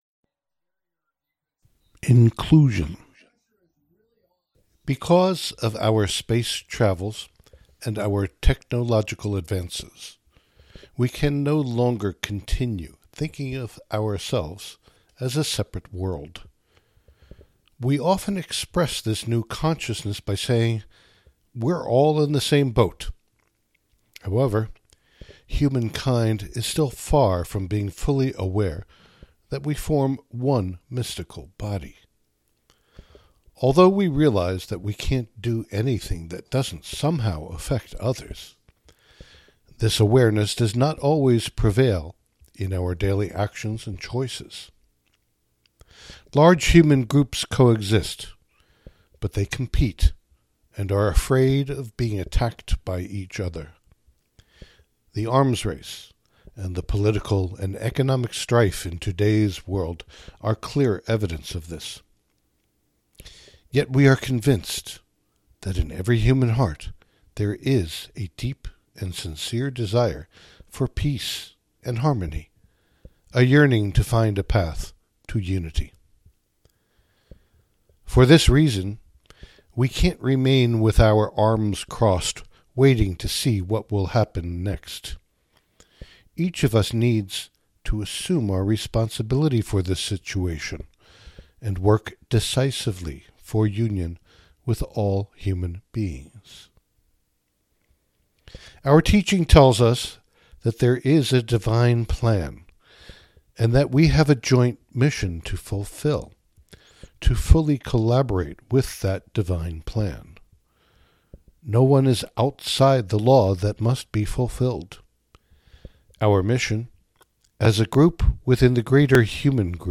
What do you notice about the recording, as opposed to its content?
Though the address below was delivered to an assembly of members of Cafh in Olmué, Chile, in May 2017, Seeds believes that everyone who is dedicated to spiritual unfolding for their own good and the good of all human beings will find it inspiring and helpful.